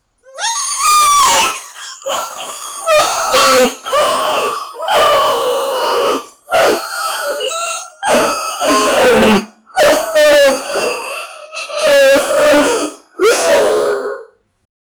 Royalty-free bars sound effects